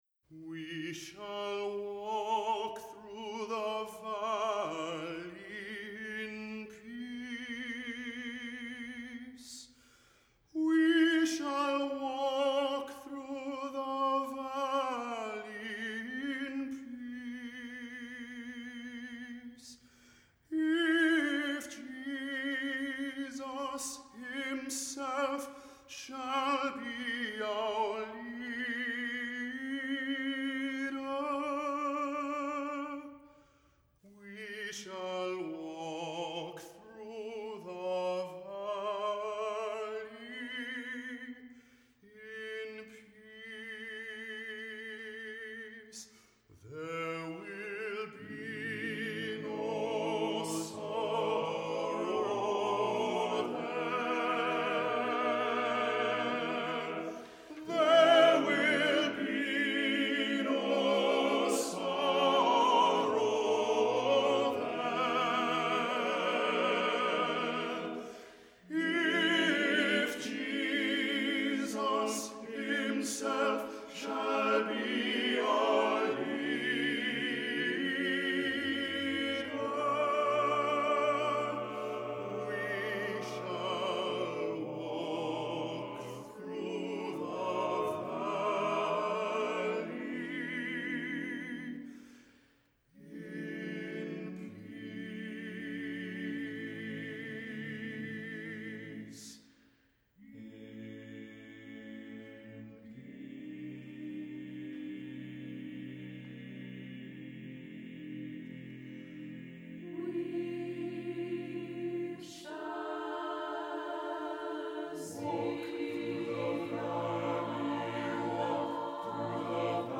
Accompaniment:      A Cappella, Piano reduction
Music Category:      Christian